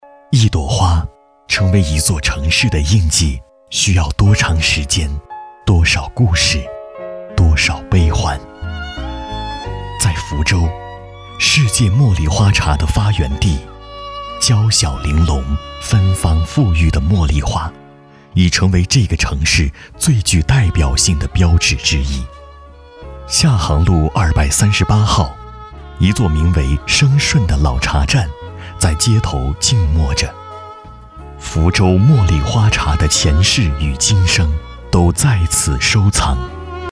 茉莉花旁白配音男355号
自然诉说 微电影旁白配音